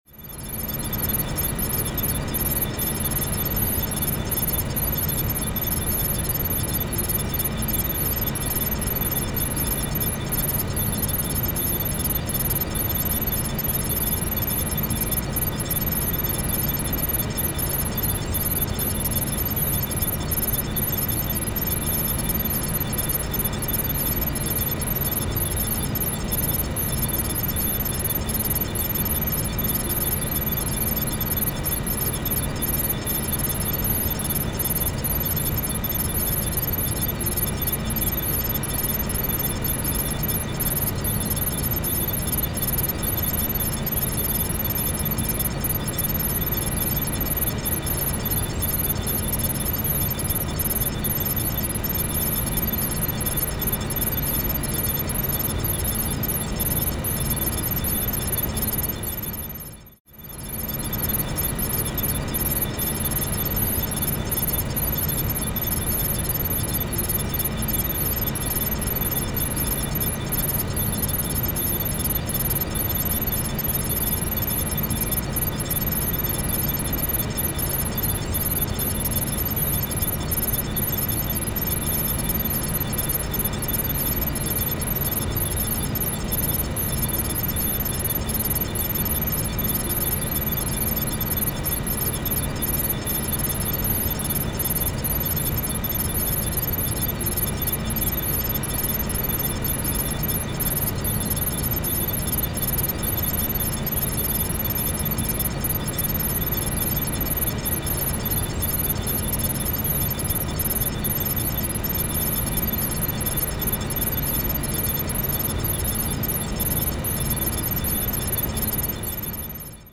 This unique timer is available as an easily downloadable MP3 file, meticulously crafted to guide you through each minute with subtle auditory cues. The magic lies in its gentle reminders, offering a serene soundscape of the money machine at work.
With a three-minute warning before the session concludes, you'll be perfectly prepared to wrap up. And when the 25 minutes are up, a cheerful 'Time's up!' chime signifies it's time to take a well-deserved break.